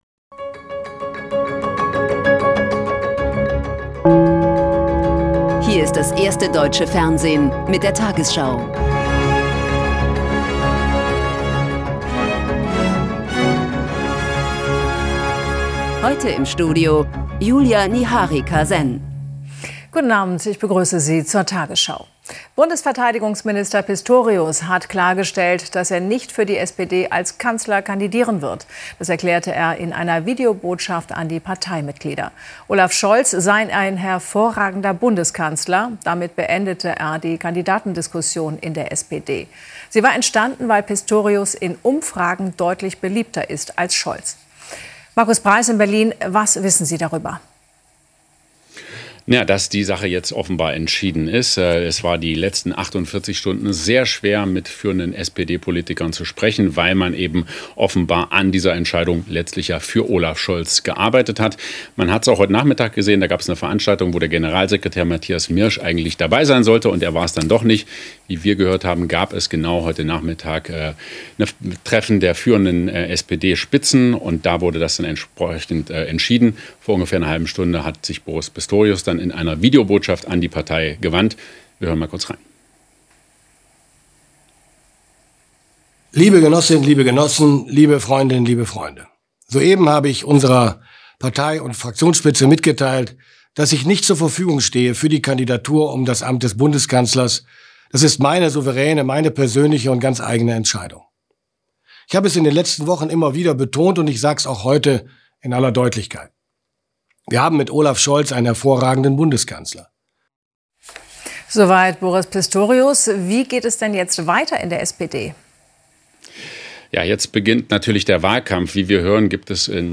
Eurodance music theme - VERS. II Remix